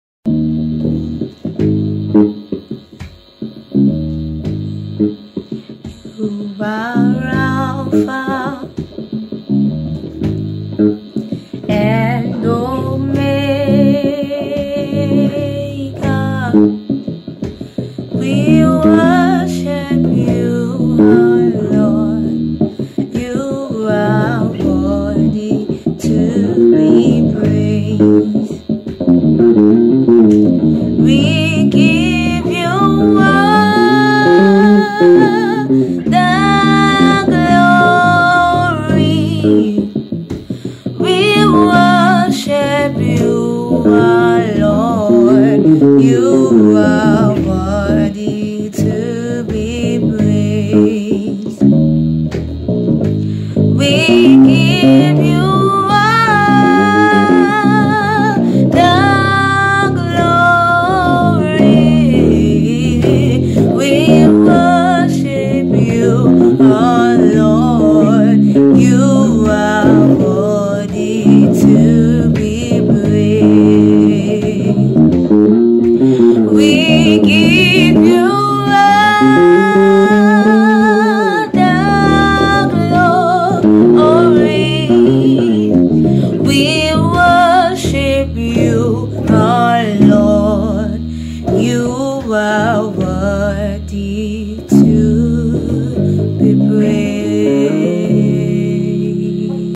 You-Are-Alpha-Omega-Basslines_.mp3